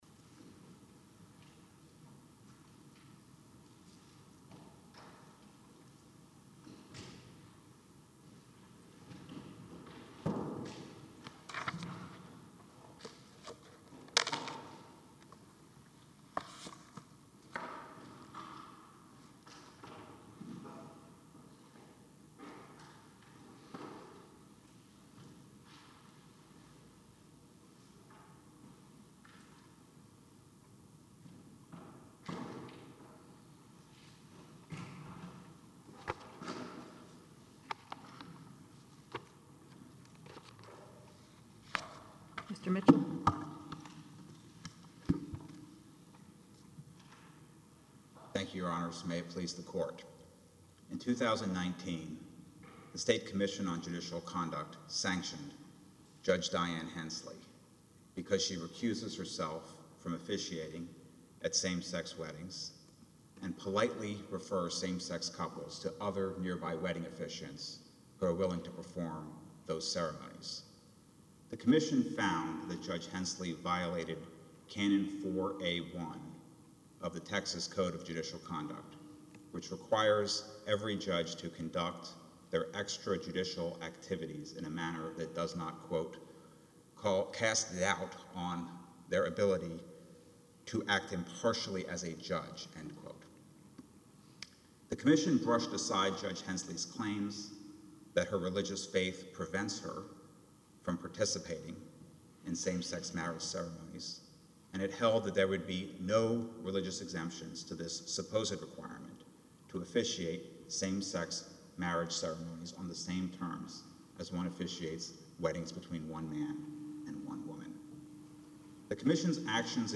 Yesterday, the U.S. 5th Circuit Court of Appeals heard oral arguments in Umphress v. Hall.